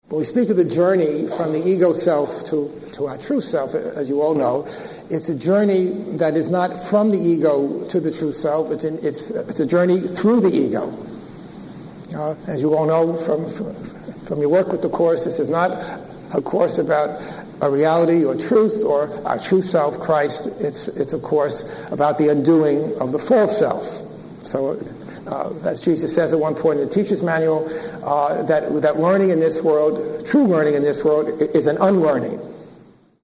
Throughout the class, questions from the audience allowed the expansion of many of these themes, as did readings from A Course in Miracles and related material.